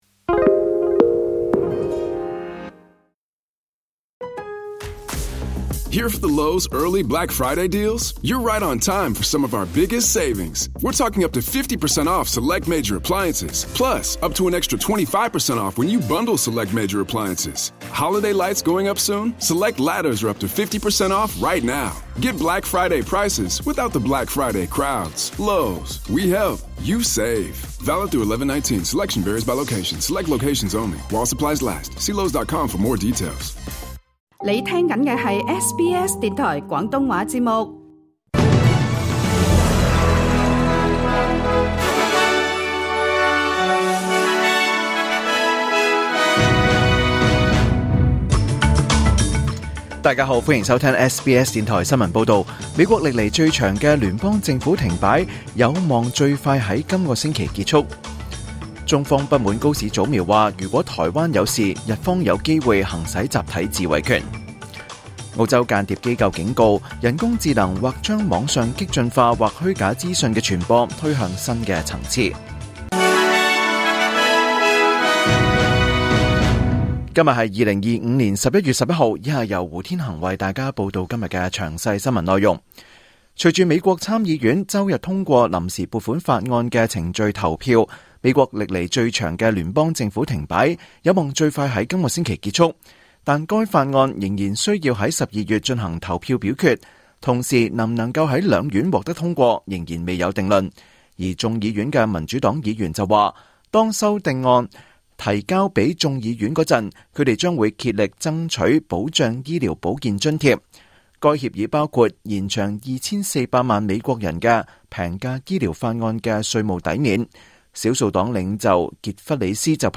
2025 年 11 月 11 日 SBS 廣東話節目詳盡早晨新聞報道。